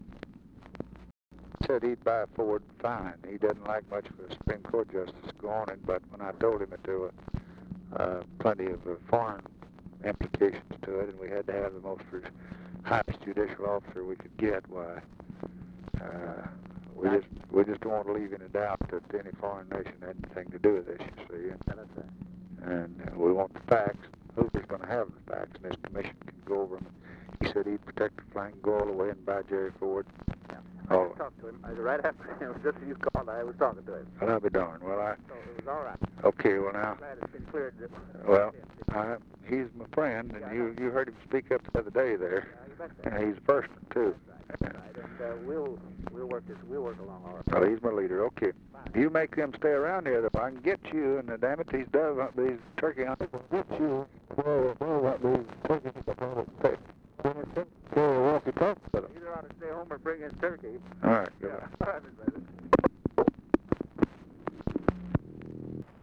Conversation with LES ARENDS, November 29, 1963
Secret White House Tapes